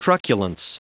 Prononciation du mot truculence en anglais (fichier audio)
Prononciation du mot : truculence